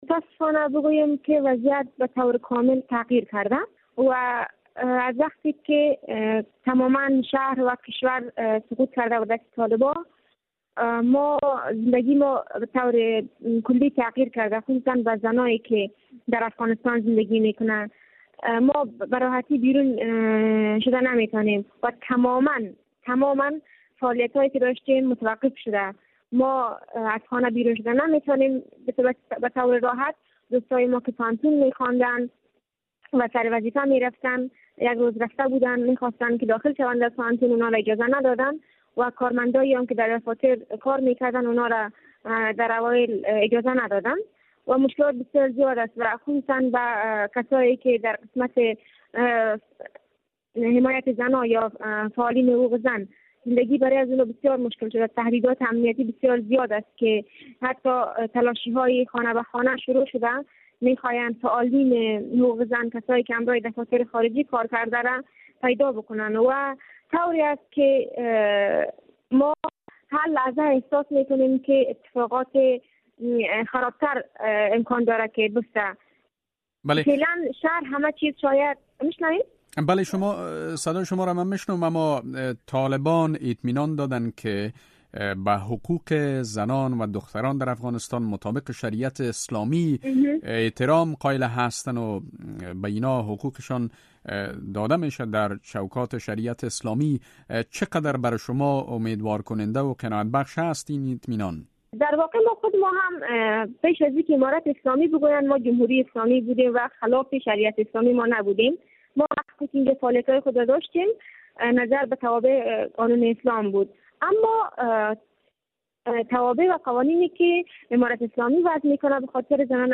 مصاحبه - صدا
خبرنگار رادیو آزادی با یکی از این زنان که به دلایل امنیتی خواست نامش افشا نشود گفت‌وگو کرده و پرسیده که در چه وضعیت به سر می‌برند؟